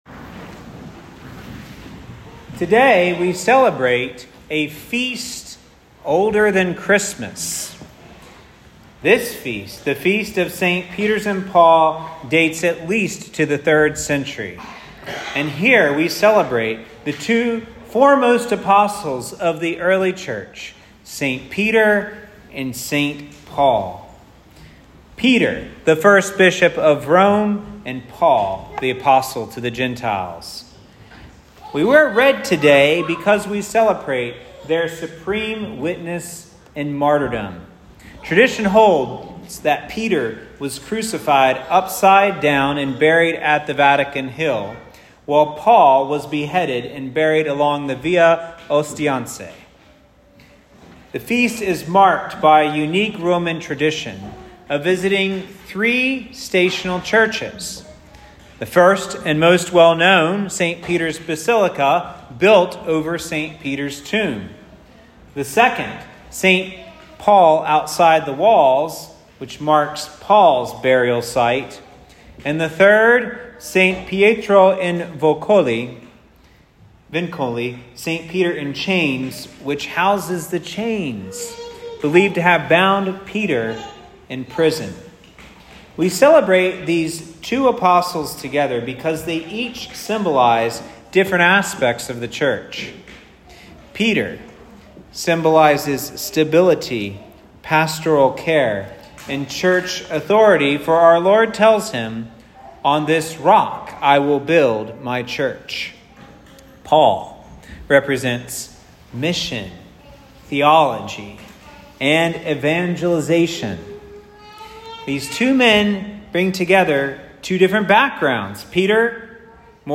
Ss. Peter & Paul Homily Ss. Peter and Paul (Ordination Anniversary).m4a